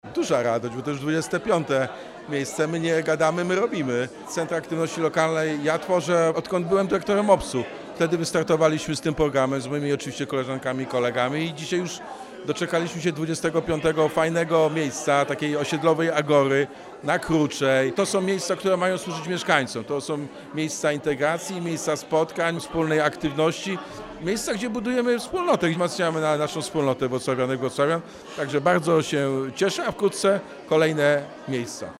– To coś znacznie więcej niż budynki. To miejsca, które tętnią życiem, bo są tworzone przez ludzi i dla ludzi – zaznacza prezydent Wrocławia Jacek Sutryk.